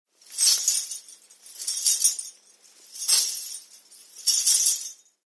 Danza árabe, bailarina haciendo el movimiento de camello 02
Sonidos: Música
Sonidos: Acciones humanas